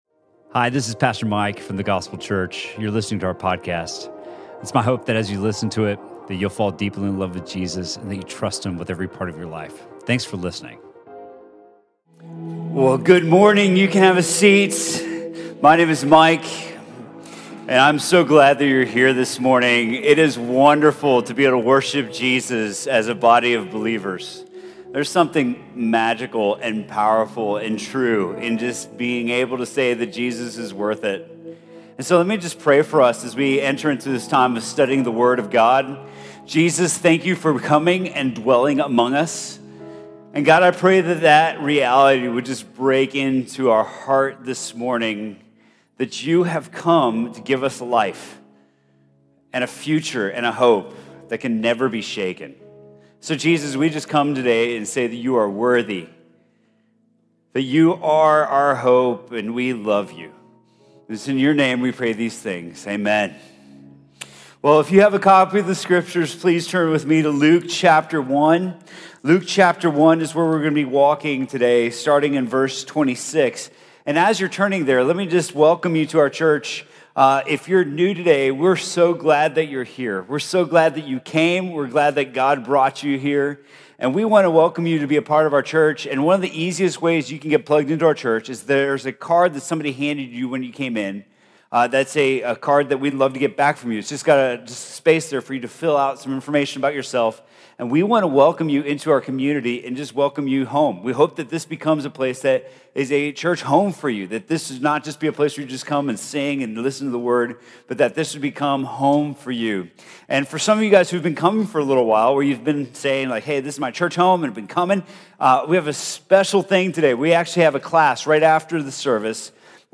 Sermon from The Gospel Church on December 9th, 2018.